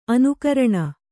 ♪ anukaraṇa